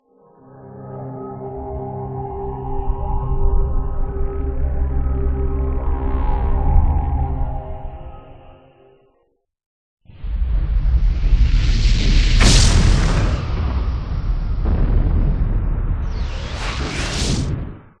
Музыка для вступления фильма или трека